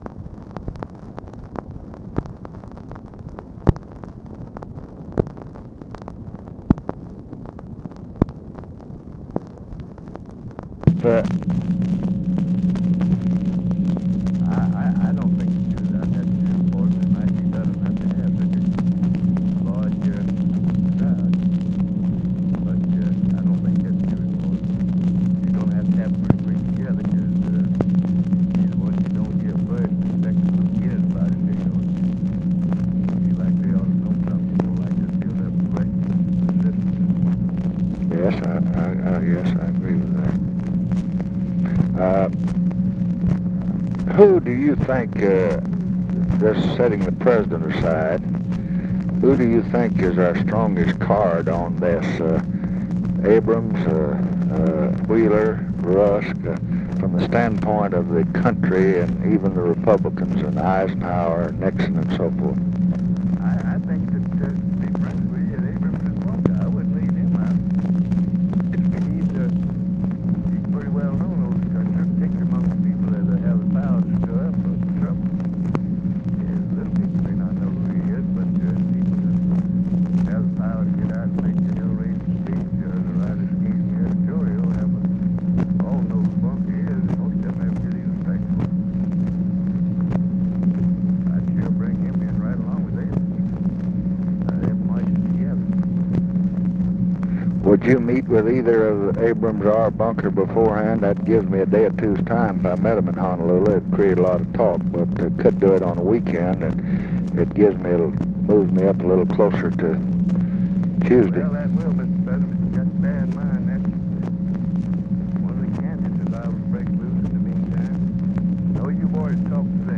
Conversation with RICHARD RUSSELL, October 23, 1968
Secret White House Tapes